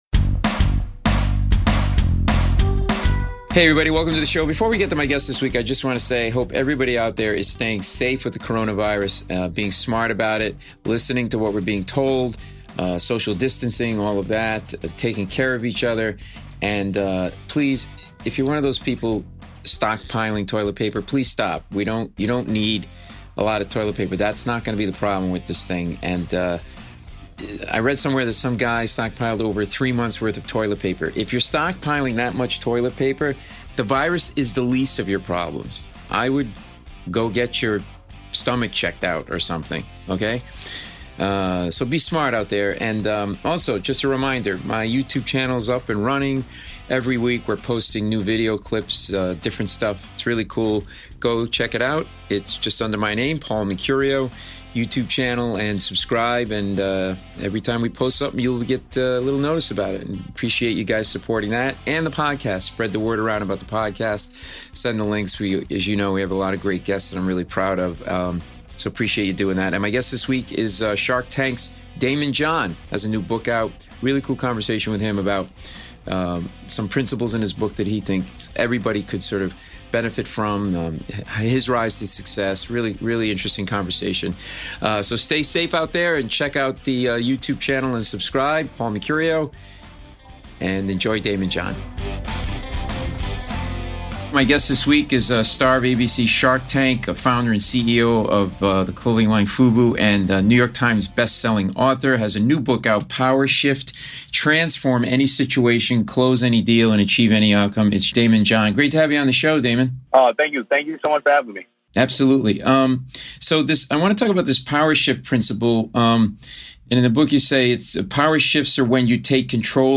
"Shark Tank's" - Daymond John (Paul Mecurio interviews Daymond John; 18 Mar 2020) | Padverb